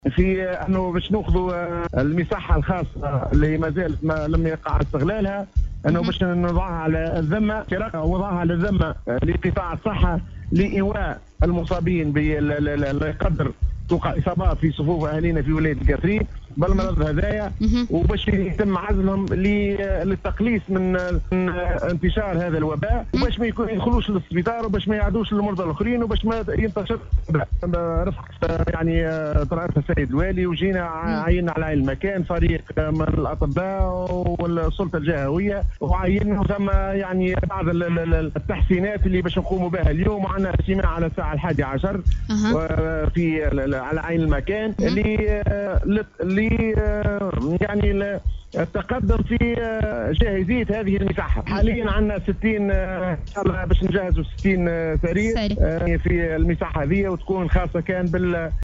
تحدث المدير الجهوي للصحة الدكتور عبد الغني الشعباني عبر راديو سيليوم اف ام ، الى انه تم تسخير مصحة الشعانبي  باذن من والي الجهة وبالتنسيق مع اصحابها و تجهيزها ب 60 سرير و ربطها بالتيار الكهربائي و الماء الصالح للشراب و تجهيزات الاكسيجين و ذلك قصد وضعها على الذمة كخطوة استباقية للحفاظ على صحة المواطنين و أرواحهم في اطار التوقي من فيروس الكورونا المستجد .